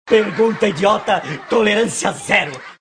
Bordão do personagem Saraiva em Zorra Total.